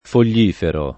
[ fol’l’ & fero ]